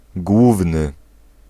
Ääntäminen
Synonyymit maître Ääntäminen France: IPA: [dɔ.mi.nɑ̃] Haettu sana löytyi näillä lähdekielillä: ranska Käännös Ääninäyte Adjektiivit 1. główny {m} 2. wyższy Suku: m .